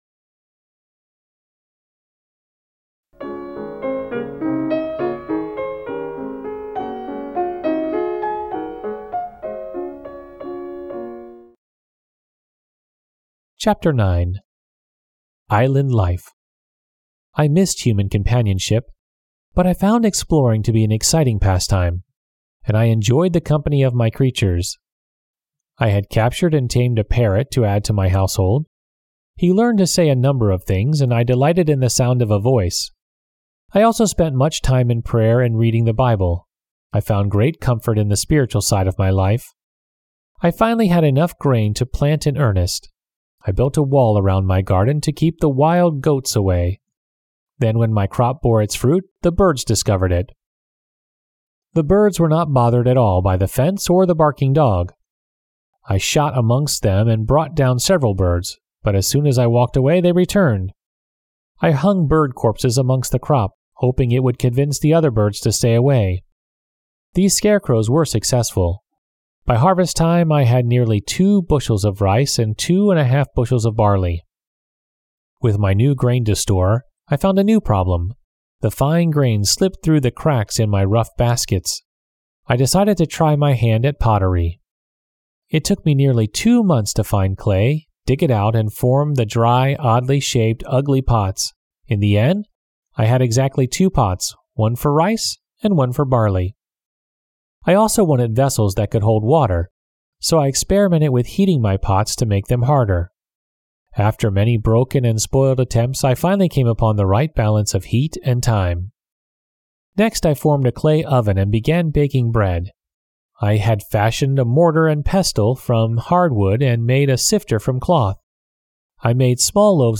丛书甄选优质中文译本，配以导读、作家作品简介和插图，并聘请资深高考听力卷主播朗读英语有声书。